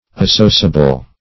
Associable \As*so"cia*ble\, a. [See Associate.]